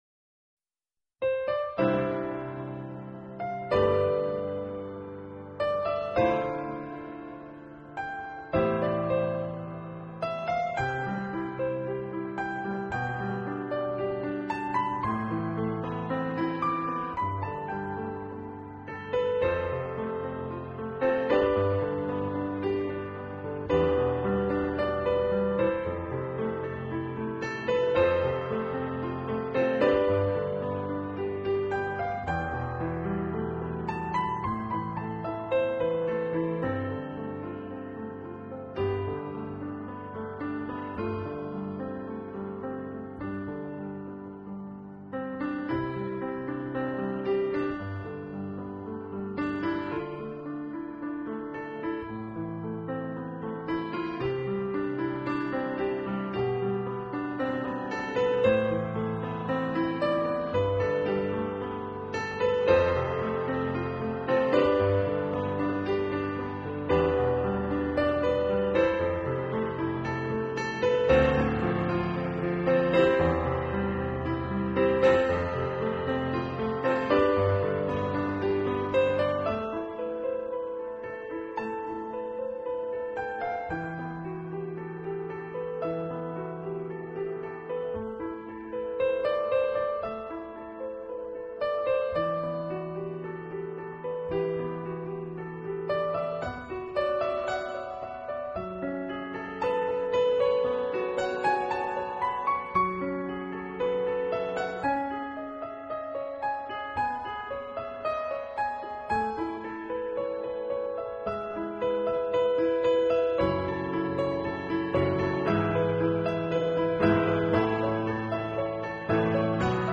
【纯乐钢琴】
类型: Neo-Classic / New Age
录制运用的技术能让你感到你在一个